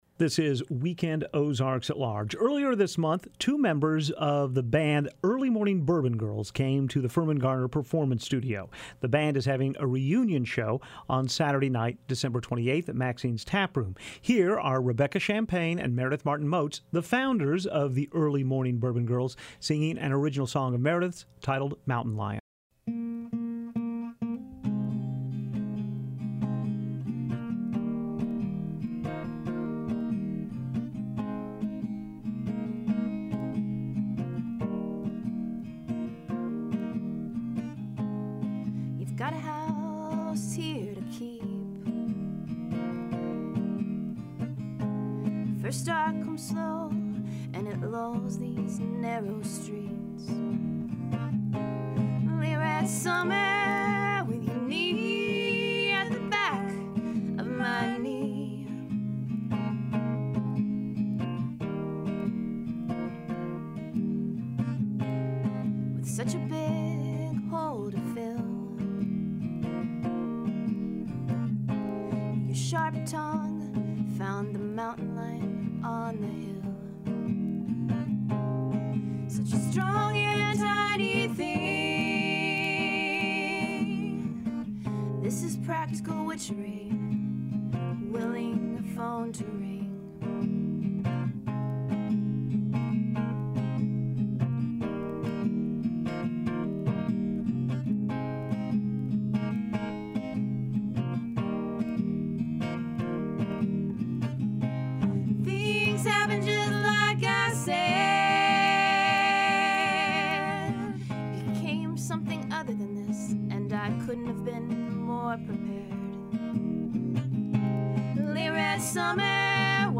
inside the Firmin Garner Performance Studio